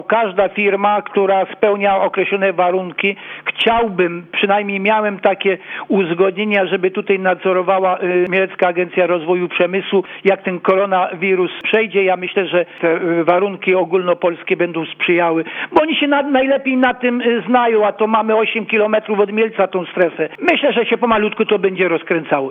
Przedsięwzięcie to umożliwi dojazd do terenów przeznaczonych pod zabudowę przemysłową bo jak tłumaczy burmistrz Radomyśla Wielkiego Józef Rybicki chciałby aby i tu również zawitały firmy, które stworzyłyby park przemysłowy.